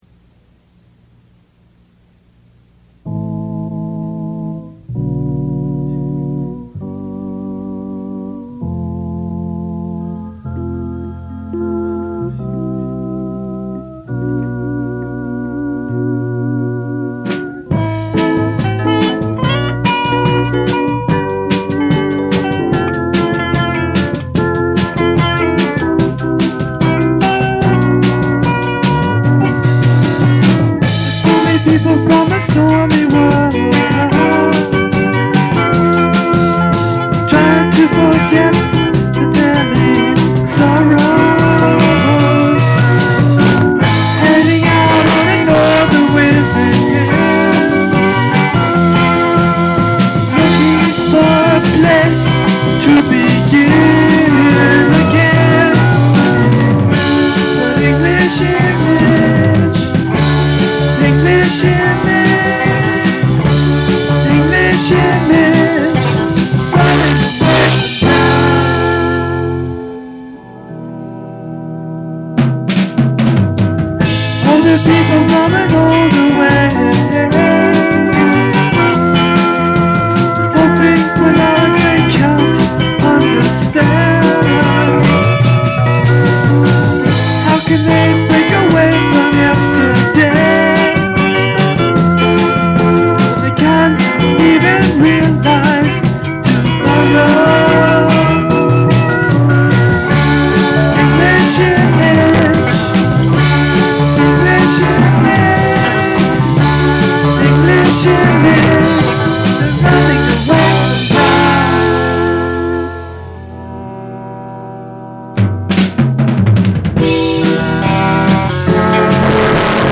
percussion, vocals
bass guitar
keyboards, vocals
guitar, vocals